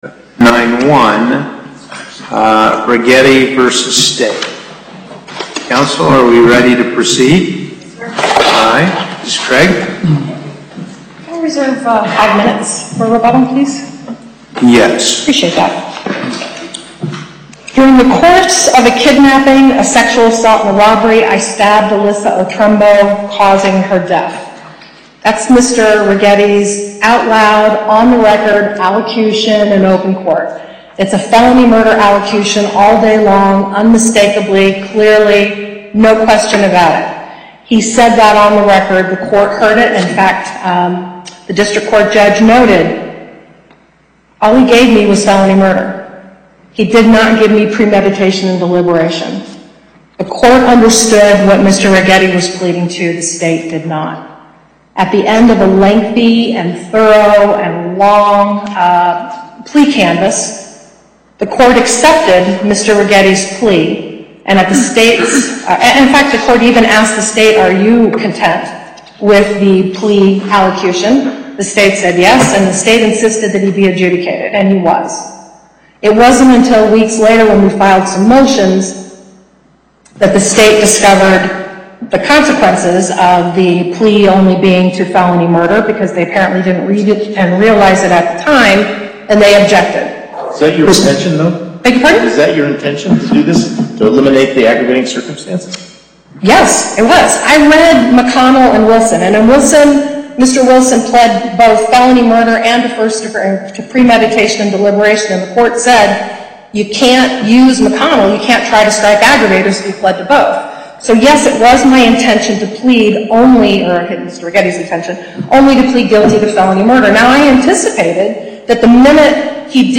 Location: Las Vegas Before the En Banc Court - Chief Justice Parraguirre, Presiding